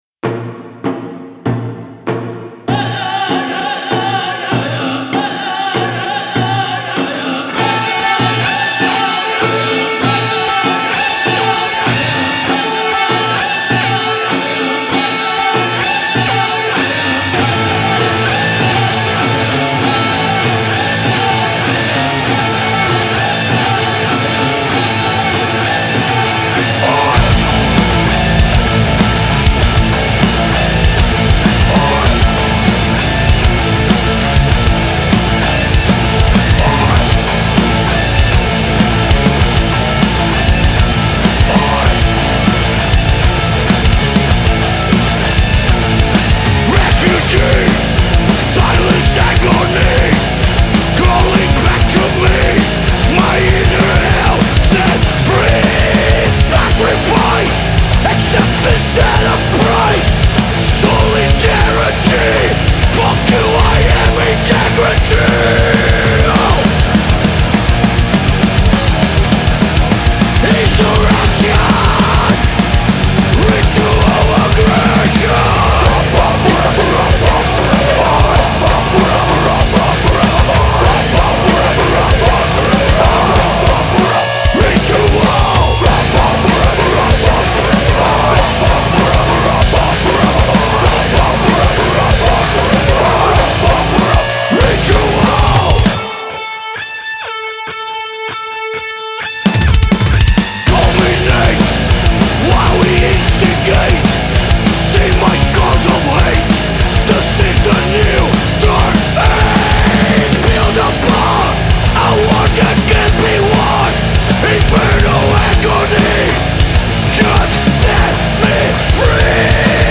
Genre : Trash Metal